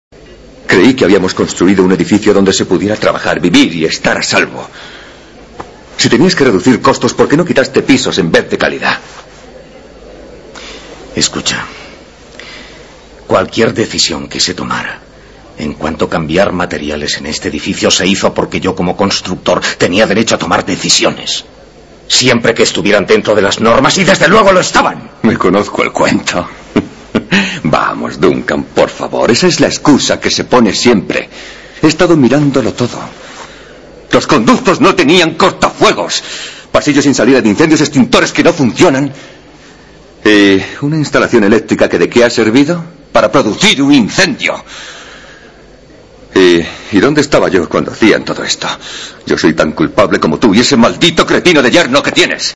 Se trata de un redoblaje moderno, todas las voces originales han desaparecido, aunque por suerte se ha mantenido la traducción original.
mismo fragmento en el nuevo doblaje.
La música se conserva en estéreo, pero las voces son monoaurales y aparentemente los efectos de sonido también; además, se ha perdido bastante dinámica en música y efectos, por comparación con la pista original en inglés. Afortunadamente, encontramos una buena calidad para voces y en en cuanto a efectos y banda sonora, por lo menos, no hay ruido de fondo ni distorsiones.